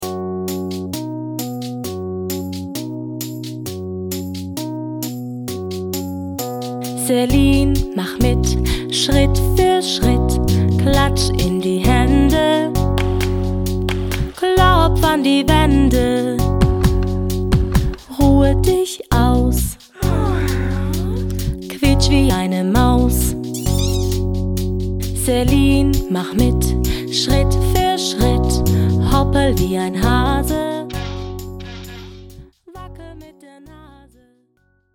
Personalisierte Kinderlieder für den ganzen Tag.
bei dem es jede Menge witzige Geräusche gibt.